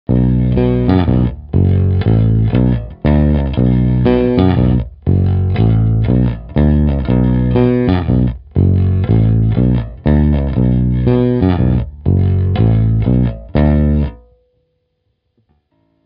krk